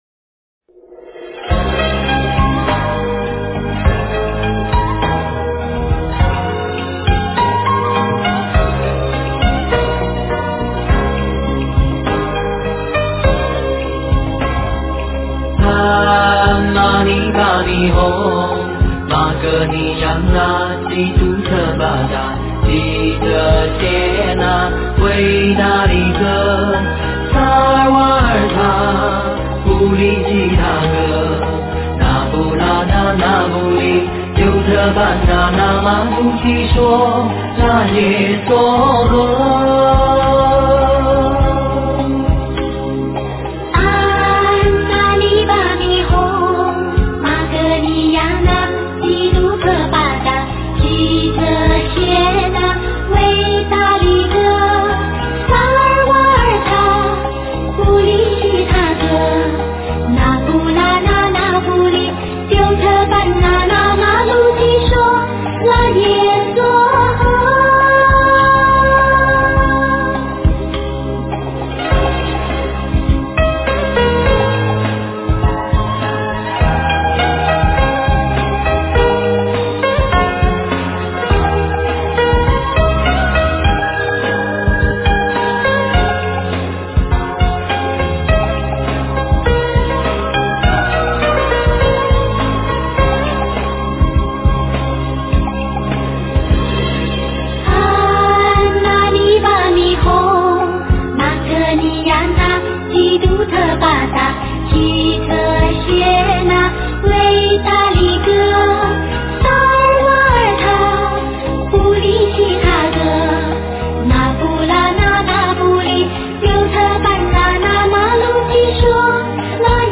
观音灵感真言--佛音 真言 观音灵感真言--佛音 点我： 标签: 佛音 真言 佛教音乐 返回列表 上一篇： 南无护法韦驮尊天菩萨--无名氏 下一篇： 般若心经咒--佚名 相关文章 佛说阿弥陀经01--梦参法师 佛说阿弥陀经01--梦参法师...